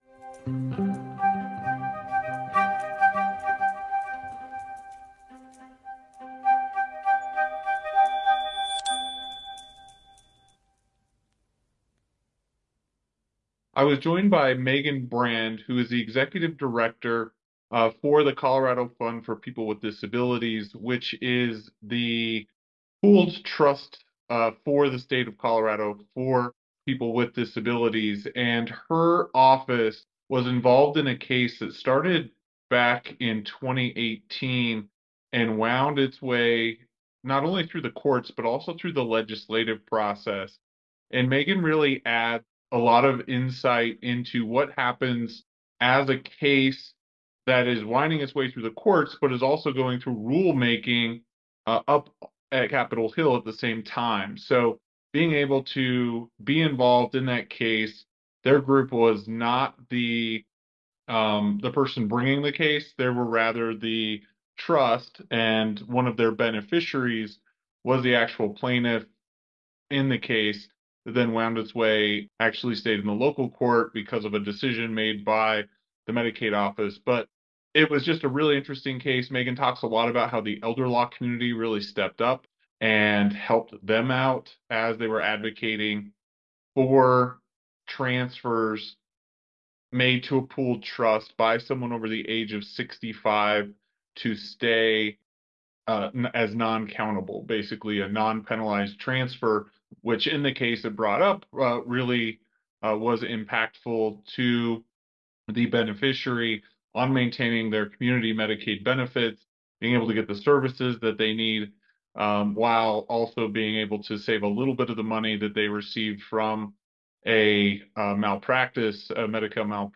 Resources referenced in the interview: CFPD Letter to Medical Services Board District Court Order MSB Hearing and Testimony – Nov. 8, 2023